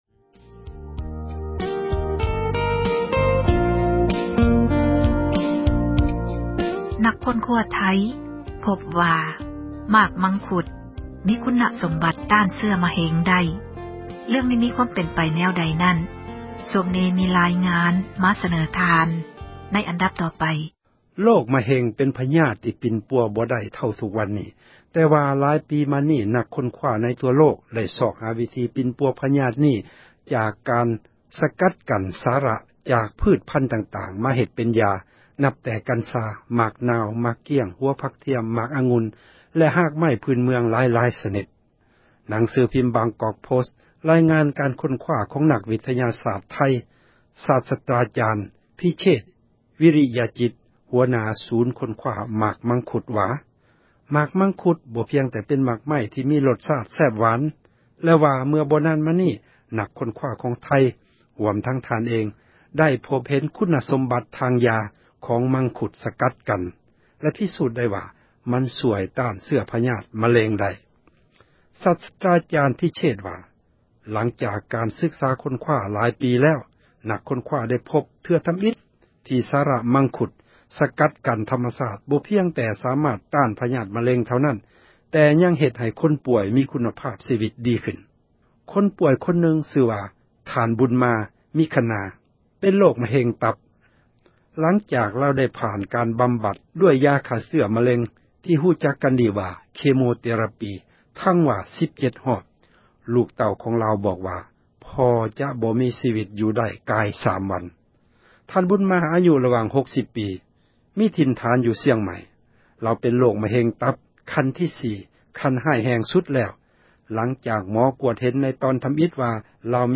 ໝາກມັງຄຸດຕ້ານ ເຊື້ອມະເຮັງ — ຂ່າວລາວ ວິທຍຸເອເຊັຽເສຣີ ພາສາລາວ